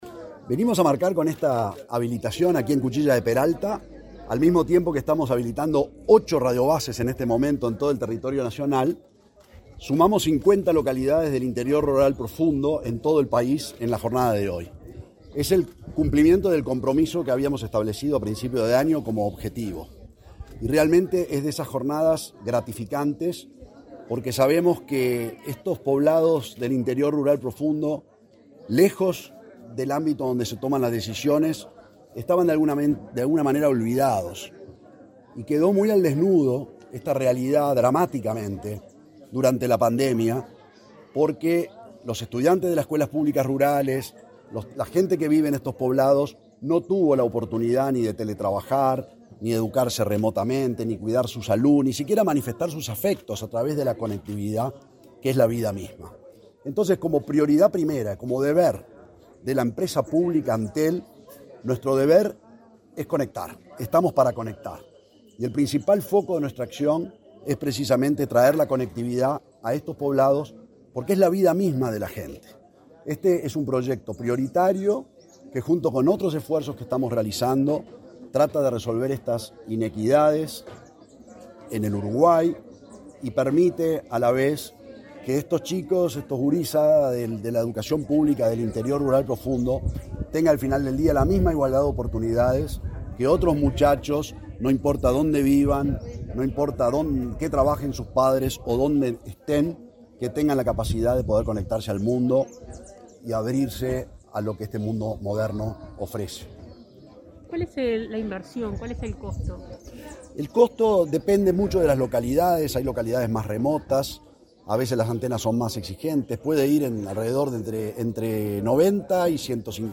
Declaraciones a la prensa del presidente de Antel, Gabriel Gurméndez
El presidente de Antel, Gabriel Gurméndez, dialogó con la prensa luego de inaugurar radiobases en el departamento de Tacuarembó.